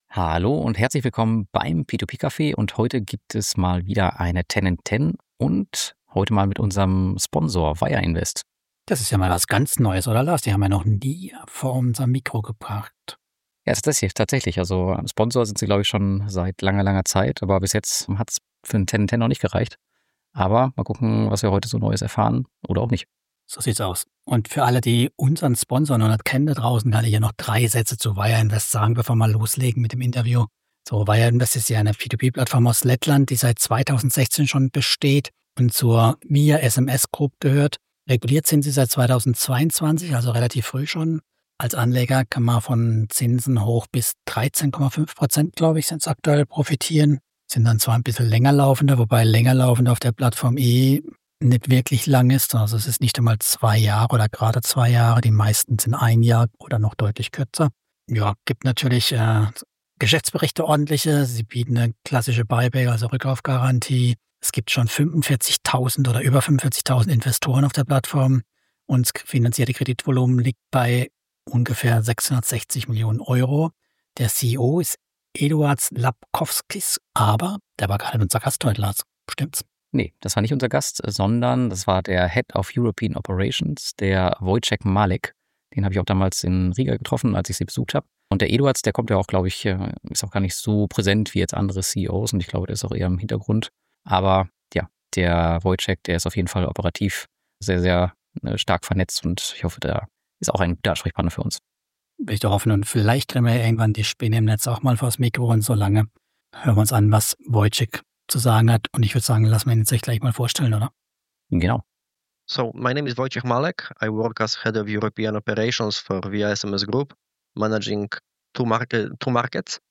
Damit das nicht langweilig wird, ist (fast) immer einen Gast mit dabei. In jüngster Zeit streamen wir das auch noch Live.